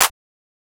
TS Clap_8.wav